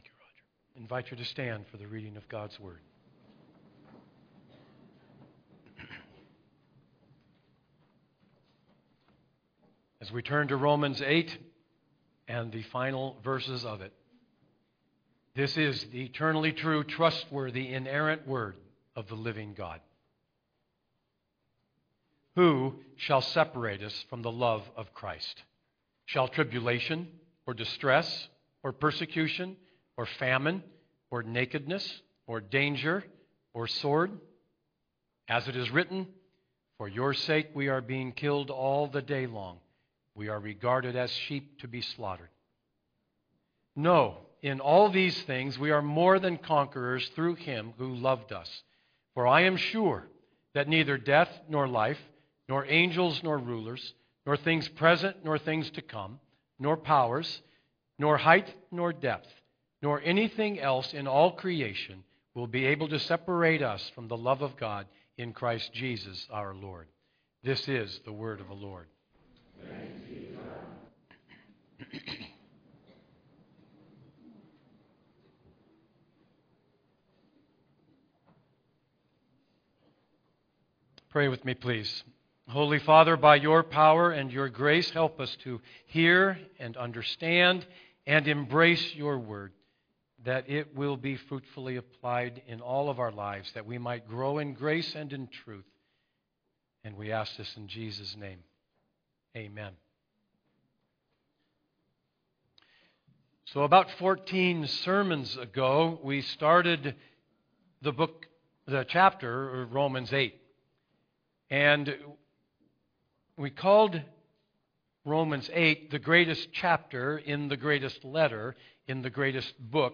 Download Sermon Notes Listen & Download Audio Series